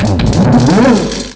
cries